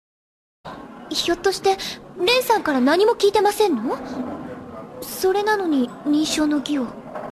Stimmen der Hauptcharaktere
Seiyuu: Mai Nakahara (Miu, DearS)